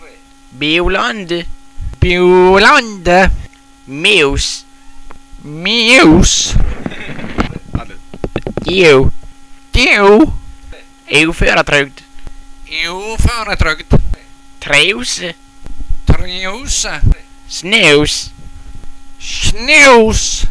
Enj lyde so helde seg meir stabile,  e denj andre ”u” – lyden so
iu- lyda som lydfile.